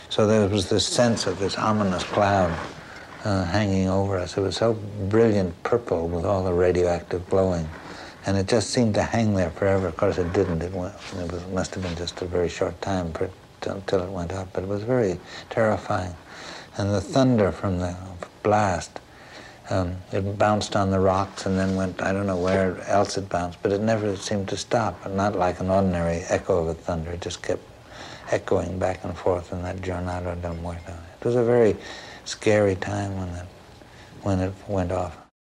In our search for understanding the role of sound in life with the bomb, we must turn to another form of aural record - that of eyewitness accounts. Here are a few examples, excerpted from longer oral histories: